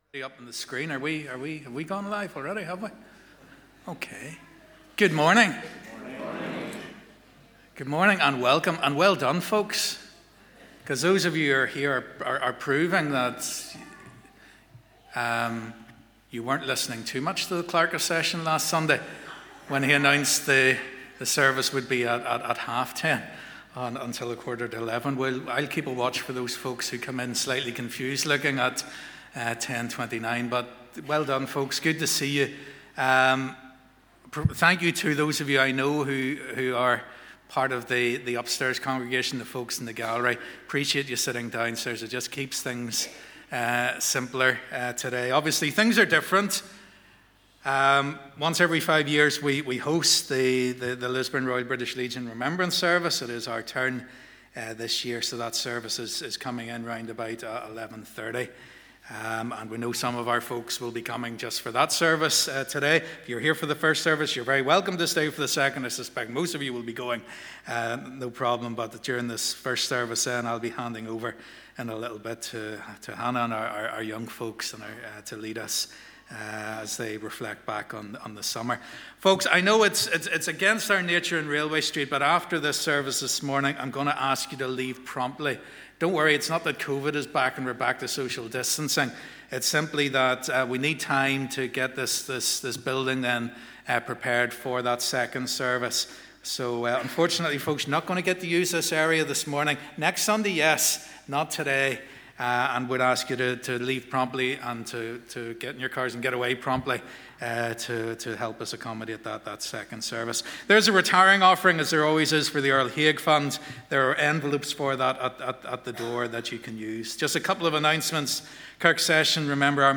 This Sunday we will be hearing of how God was at work among our young people over the summer months. We will hear from our Exodus teams and their time in Romania, as well as about our Ganaway summer camp.